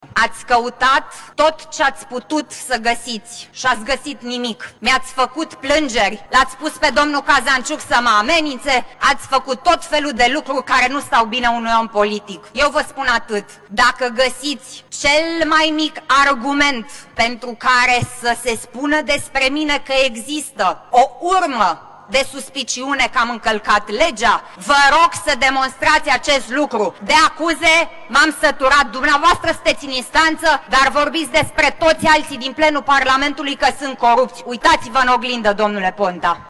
Victor Ponta și Alina Gorghiu, acuze reciproce de la tribuna Parlamentului
Co-președinta PNL – Alina Gorghiu – s-a apărat și i-a cerut premierului să vină cu dovezi.